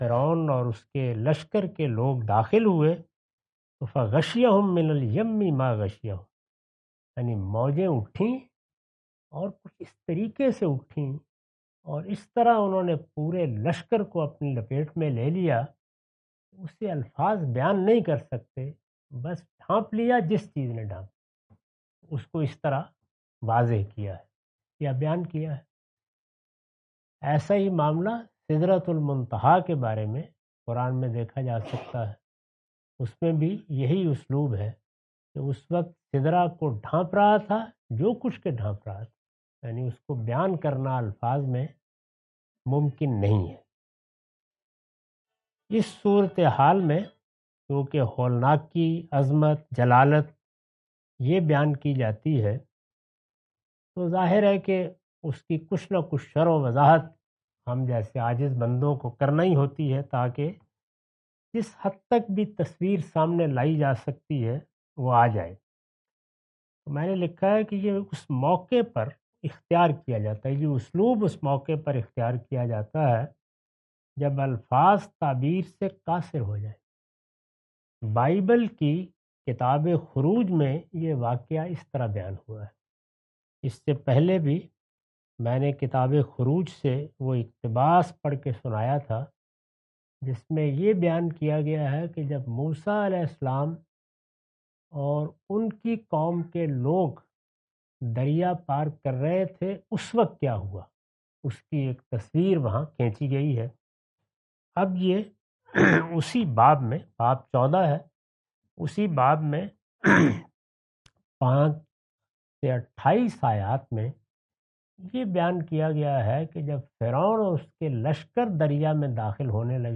Surah Taha A lecture of Tafseer-ul-Quran – Al-Bayan by Javed Ahmad Ghamidi. Commentary and explanation of verse 80.